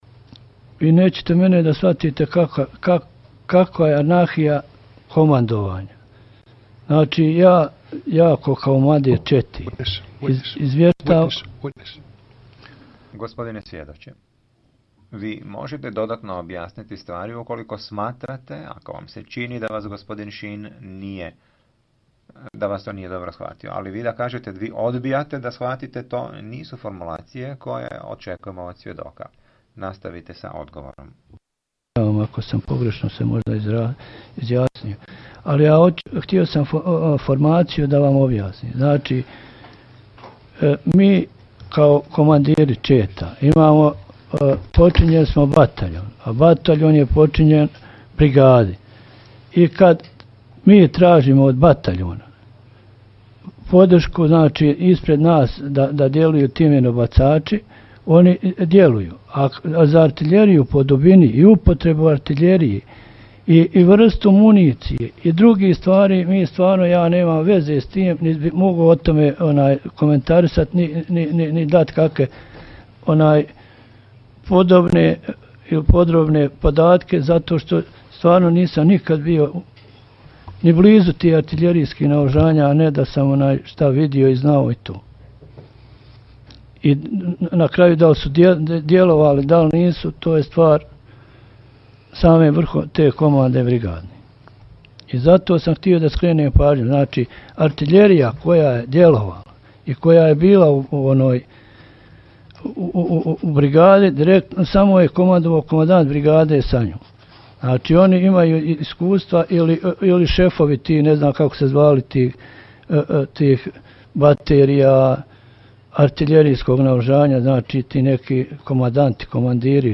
Sudac Orie ispituje svjedoka o minobacačkim napadima